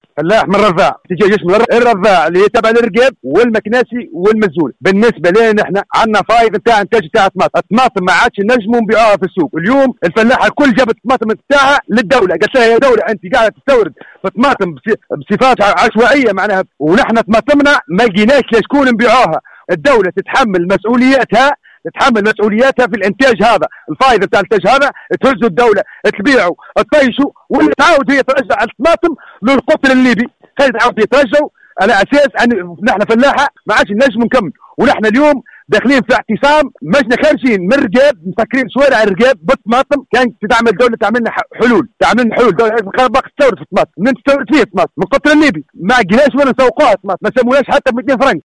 وقال احد المحتجين في تصريح لمراسل الجوهرة اف ام، إنهم عاجزون عن تسويق الإنتاج أمام استيراد الدولة لكميات كبيرة من ليبيا.